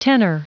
Prononciation du mot tenor en anglais (fichier audio)
Prononciation du mot : tenor